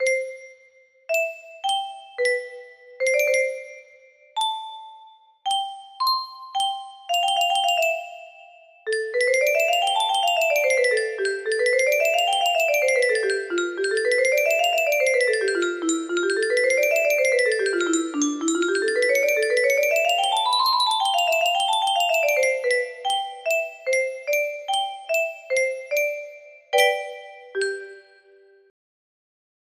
Mozart piano sonata No16 music box melody